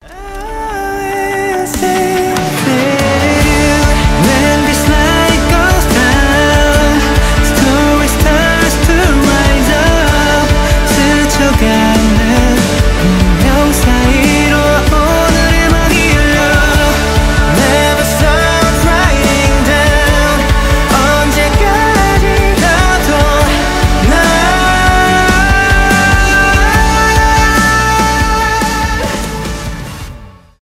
k-pop
поп